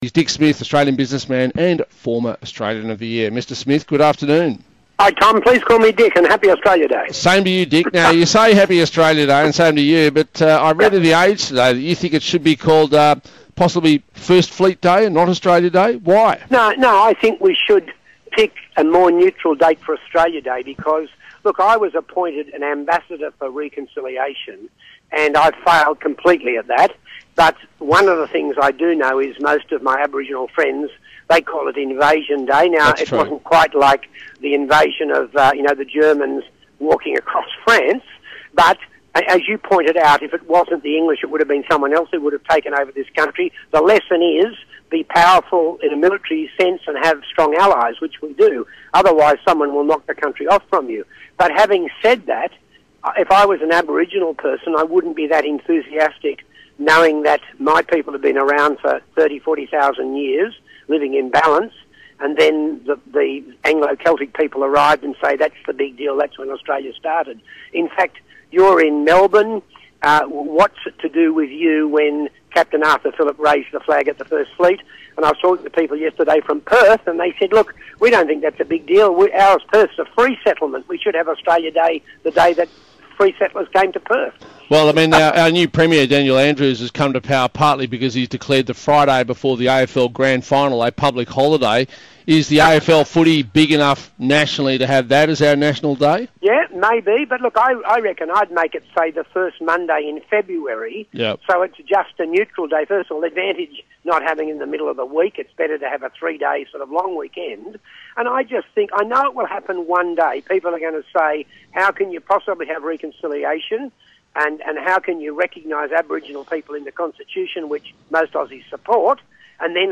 Dick Smith talks to Tom Elliott about changing Australia Day